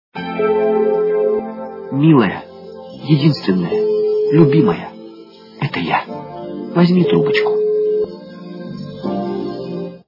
При прослушивании Мужской голос - Милая, единственная - это я. Возьми трубочку! качество понижено и присутствуют гудки.
Звук Мужской голос - Милая, единственная - это я. Возьми трубочку!